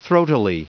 Prononciation du mot throatily en anglais (fichier audio)
Prononciation du mot : throatily